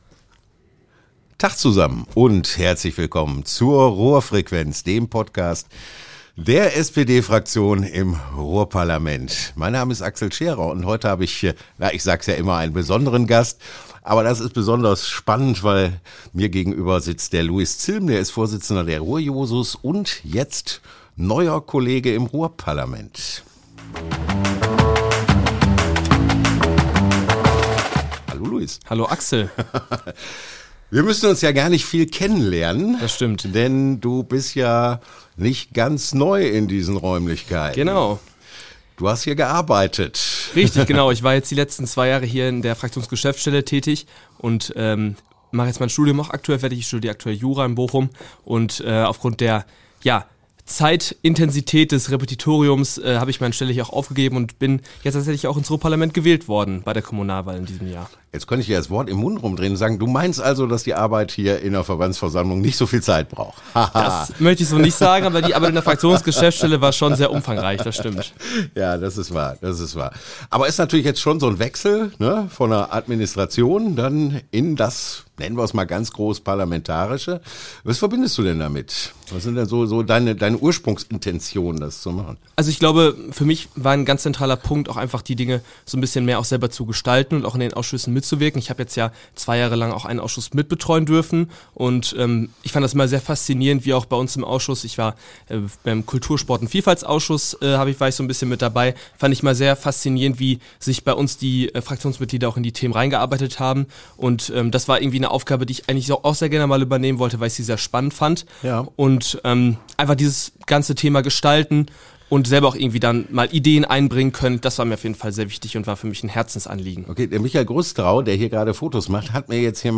Die beiden sprechen über die kommende Wahlperiode und über die Themen, die das Ruhrgebiet in der Zukunft beschäftigen werden.